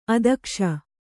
♪ adakṣa